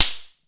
平手打ち/ビンタ１